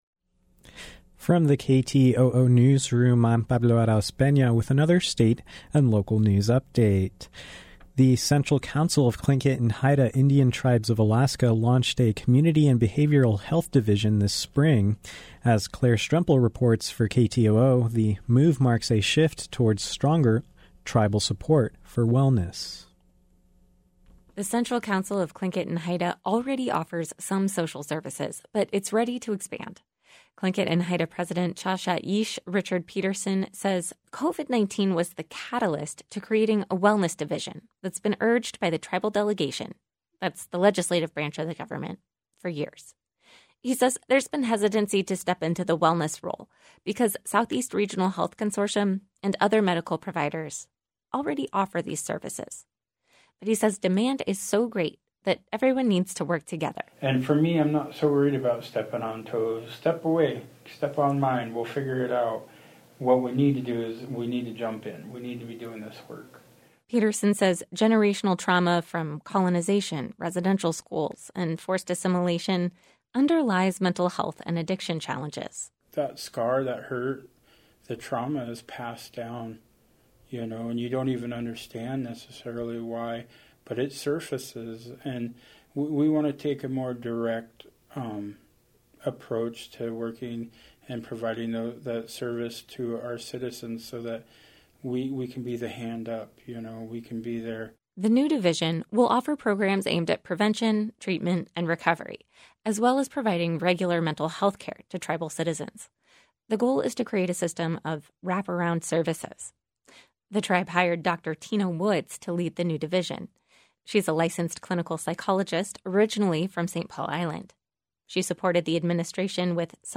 Newscast – Tuesday, June 8, 2021